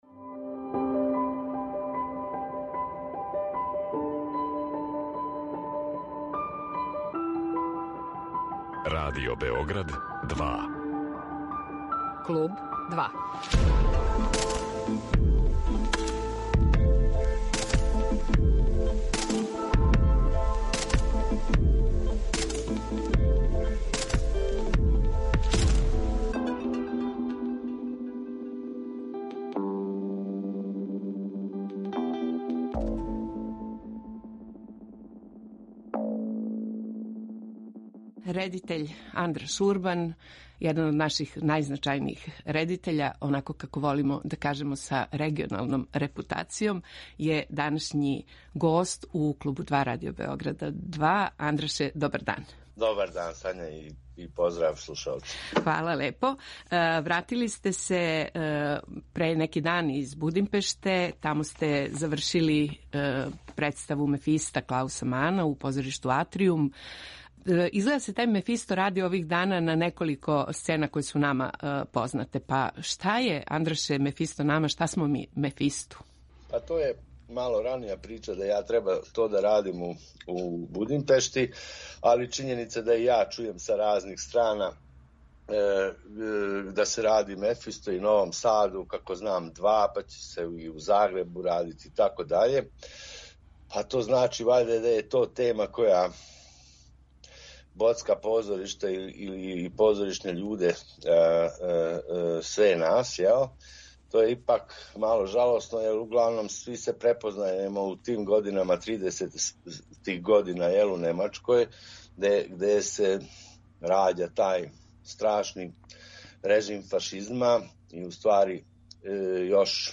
Гост је редитељ Андраш Урбан.